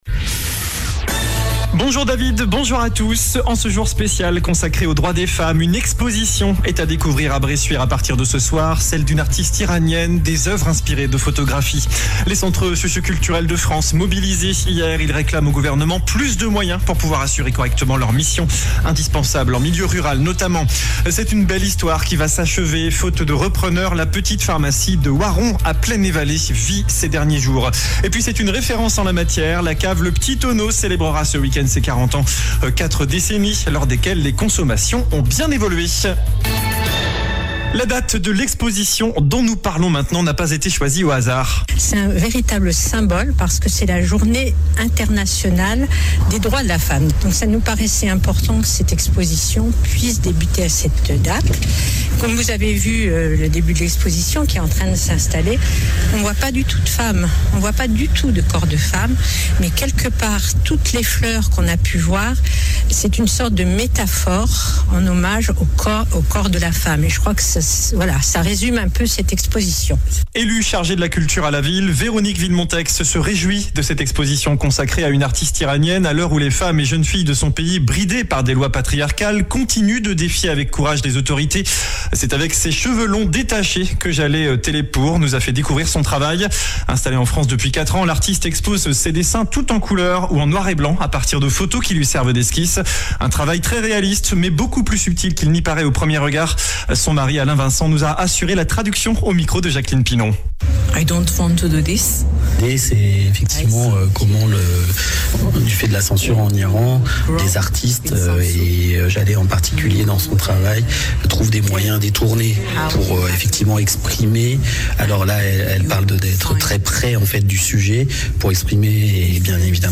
JOURNAL DU VENDREDI 08 MARS ( MIDI )